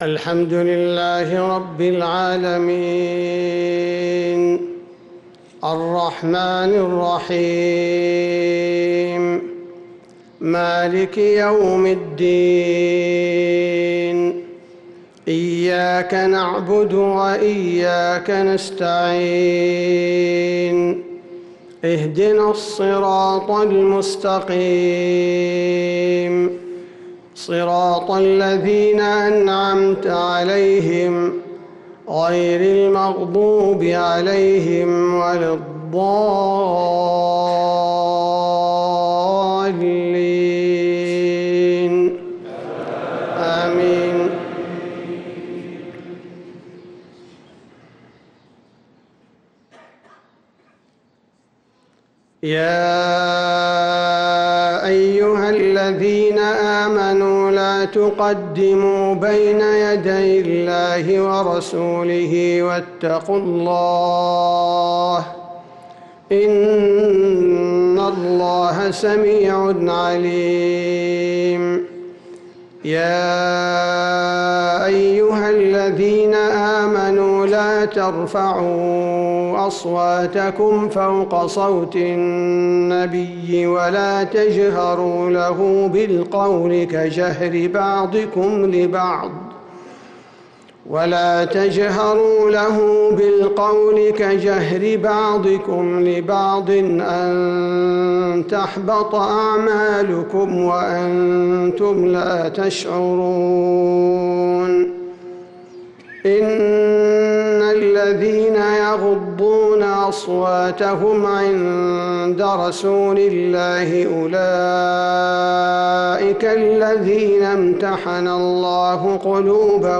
فجر الجمعة 7-9-1446هـ فواتح سورة الحجرات 1-12 | Fajr prayer from Surat al-Hujurat 7-3-2025 > 1446 🕌 > الفروض - تلاوات الحرمين